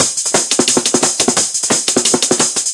颠簸的
描述：叮叮当当的苍蝇
标签： 80 bpm Drum And Bass Loops Drum Loops 1.01 MB wav Key : Unknown
声道立体声